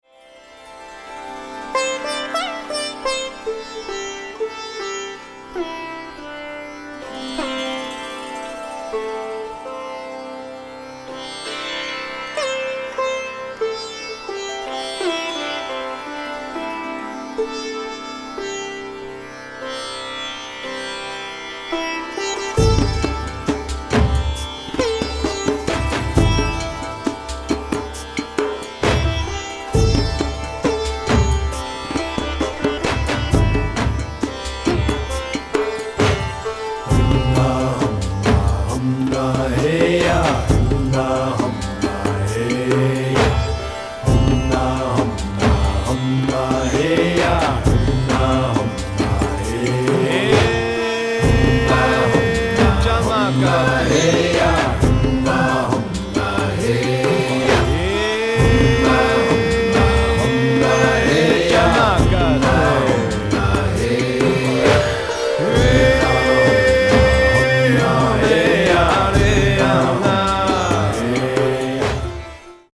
world chant and groove music